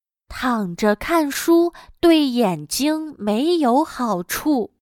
躺着看书对眼睛没有好处。/Tǎngzhe kànshū duì yǎnjīng méiyǒu hǎochù./Acostarse y leer un libro no es bueno para los ojos.